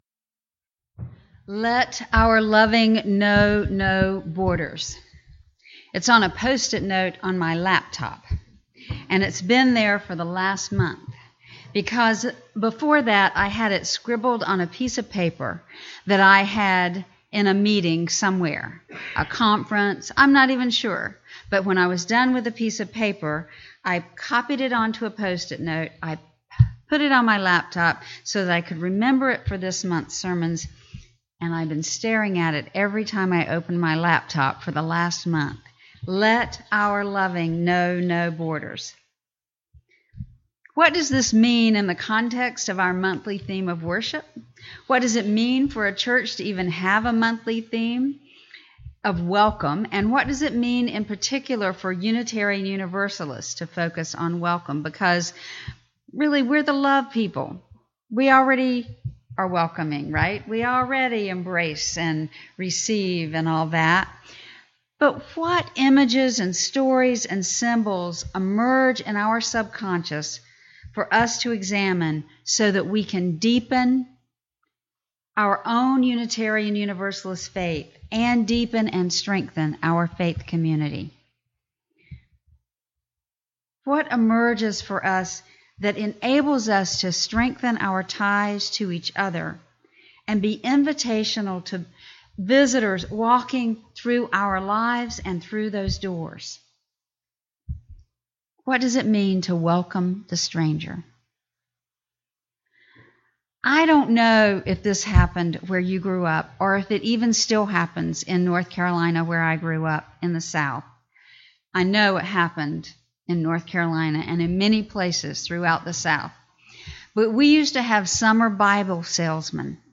This sermon explores the spiritual and practical necessity of practicing radical hospitality toward both friends and strangers.